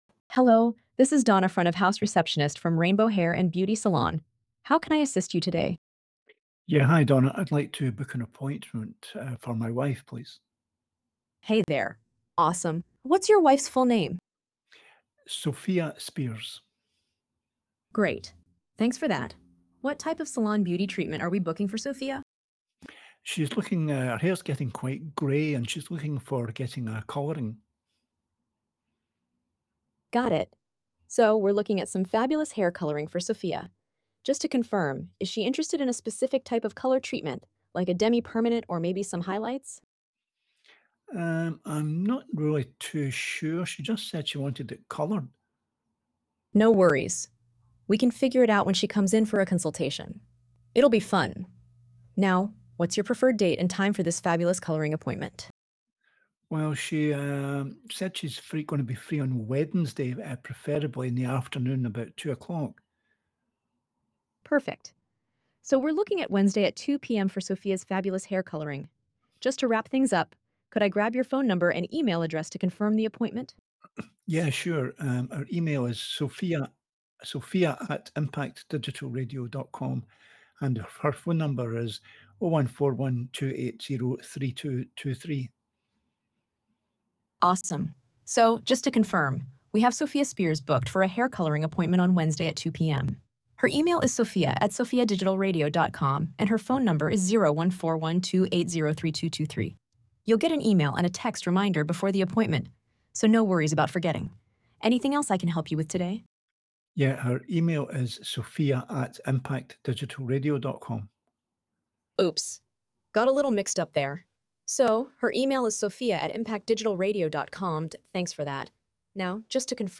Listen To Our AI Agent Donna take call from salon customers and book appointments.